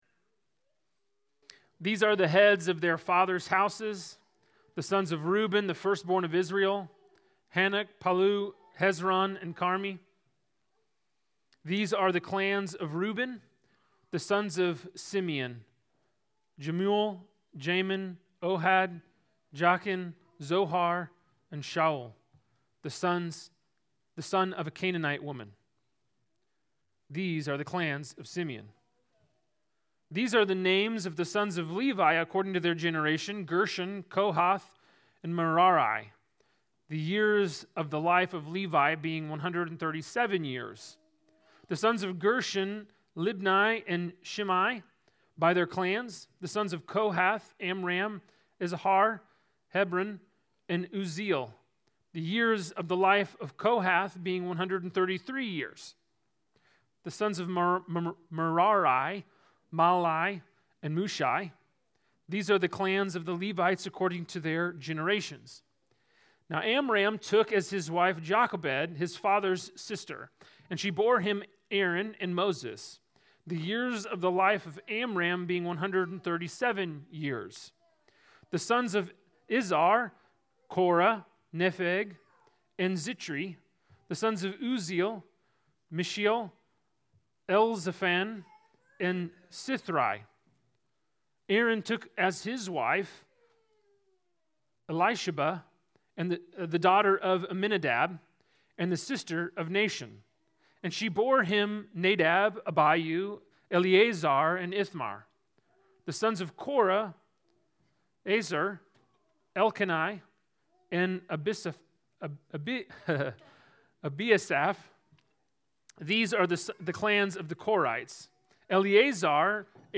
Sermons from Proclaim Church